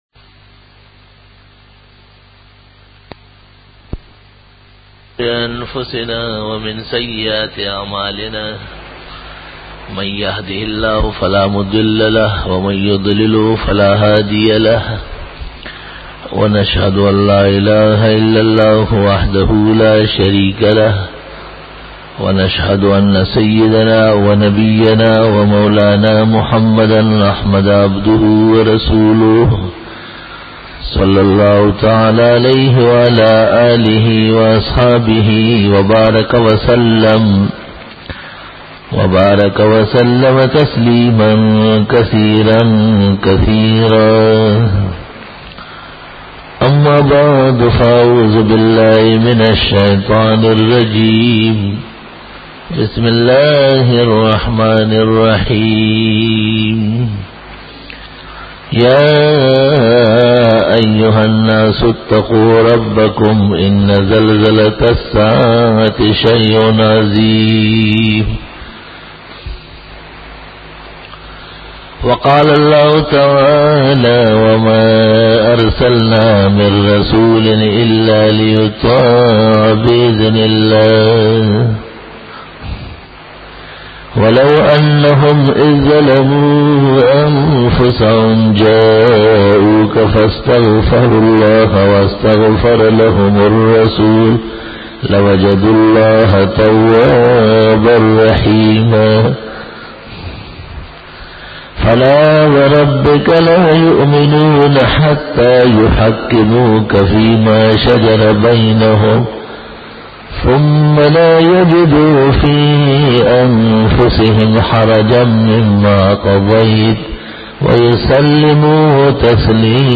002_Jummah_Bayan_11_Jan_2002
بیان جعمۃ المبارک